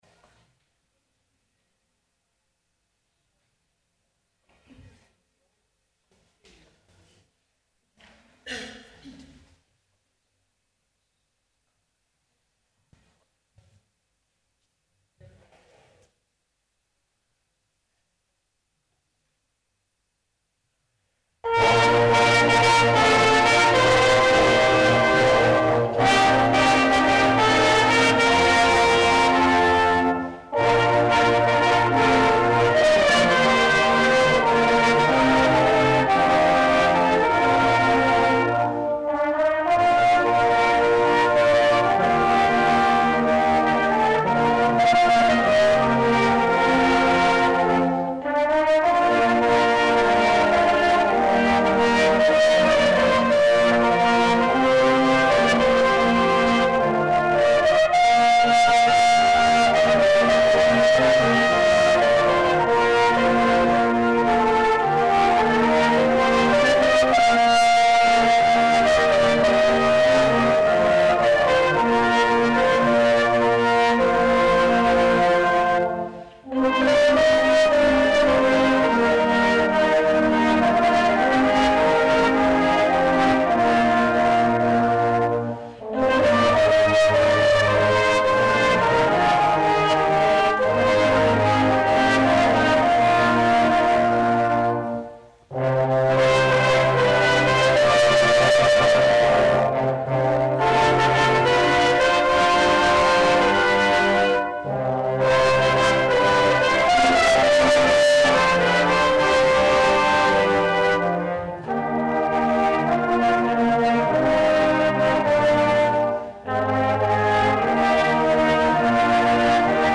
Schöpfungs-Gottesdienst organisiert von der Ökumenischen Stadtrunde.